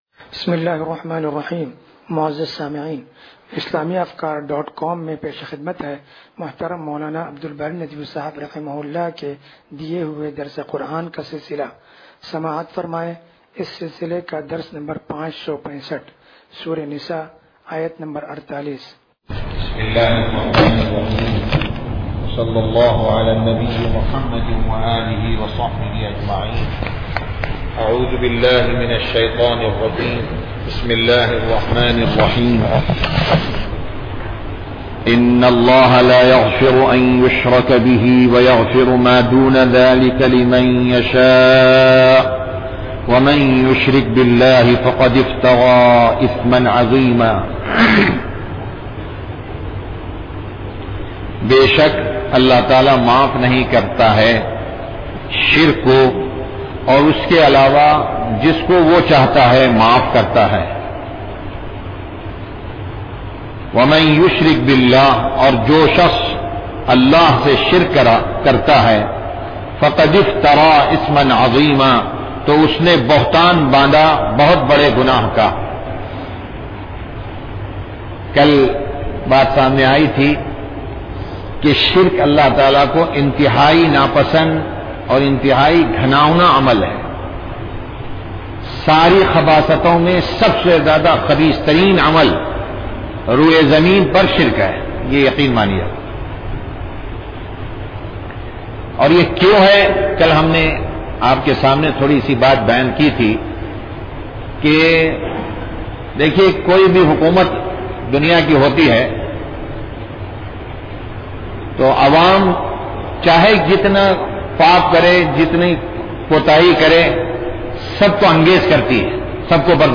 درس قرآن نمبر 0565
درس-قرآن-نمبر-0565.mp3